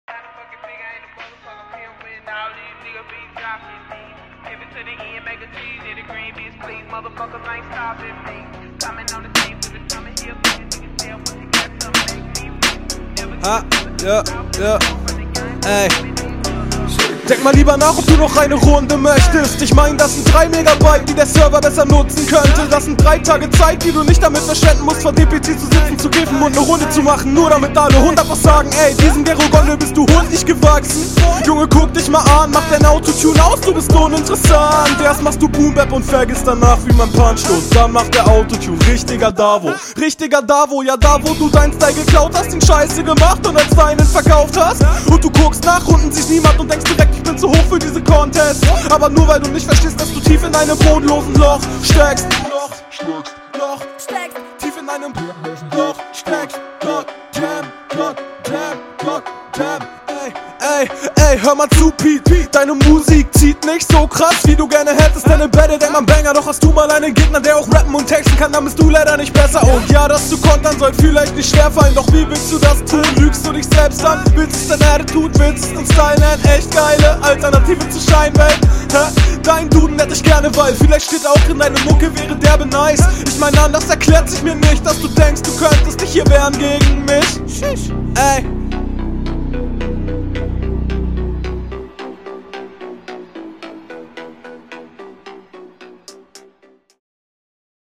Beatwahl is komisch, aber geil!